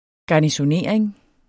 Udtale [ gɑnisoˈneˀʁeŋ ]